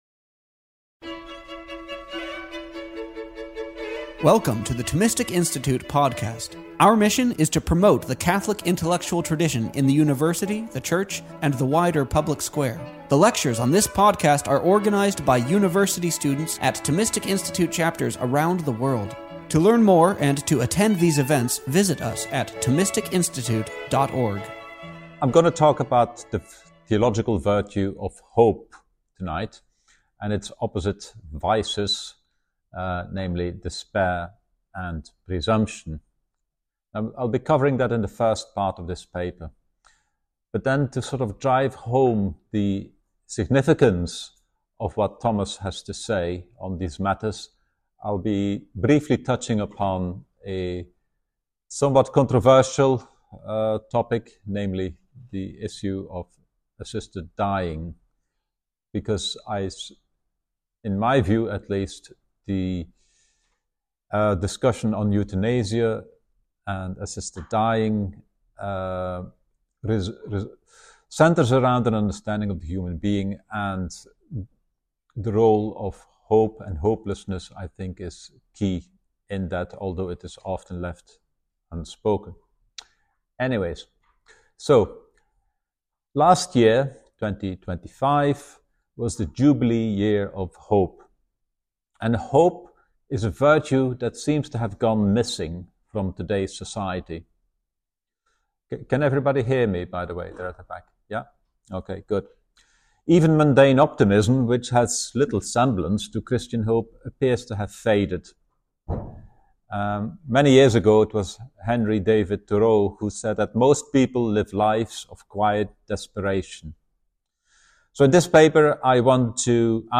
This lecture was given on November 6th, 2023, at Oxford University.